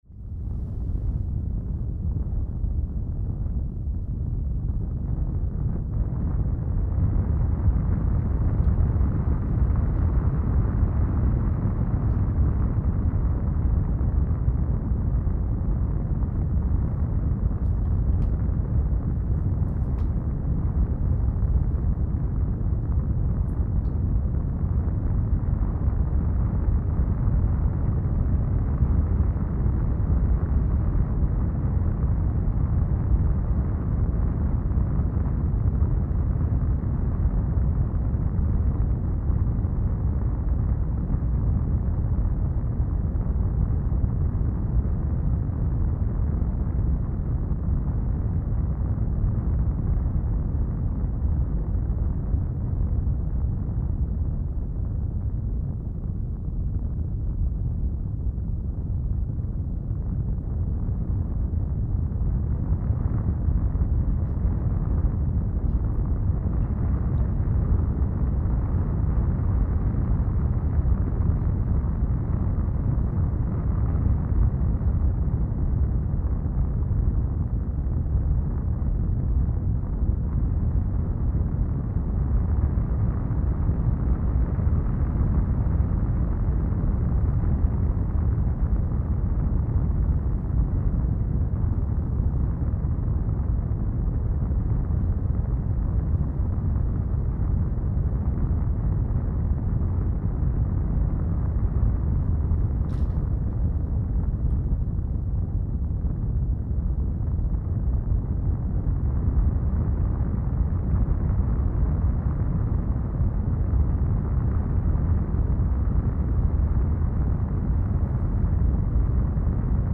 Звуки сквозняка
Воздух проникает в дом через широкую щель